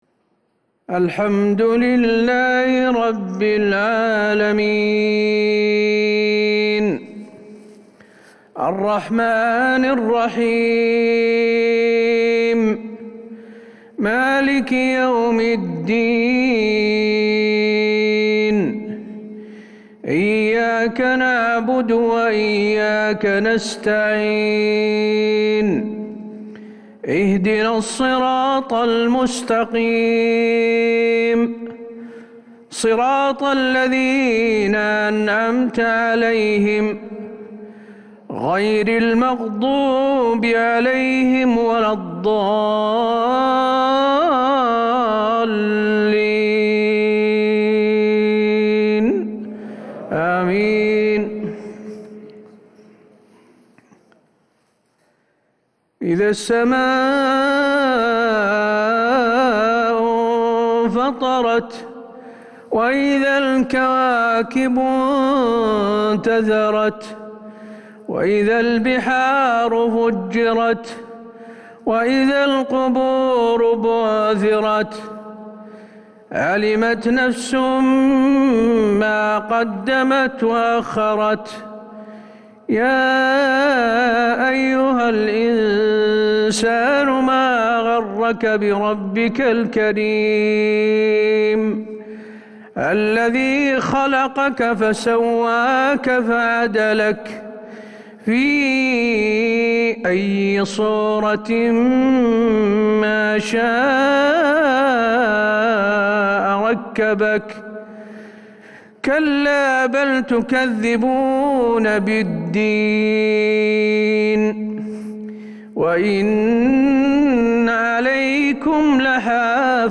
صلاة المغرب للشيخ حسين آل الشيخ 25 جمادي الأول 1442 هـ
تِلَاوَات الْحَرَمَيْن .